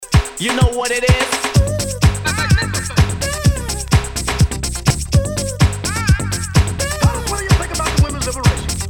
Buongiono se registro  col registratore di mb studio il file registrato salta versione mb studio 8.78.32
ho fatto tutte le prove possibile anche cambiando il buffer il file registrato salta lo stesso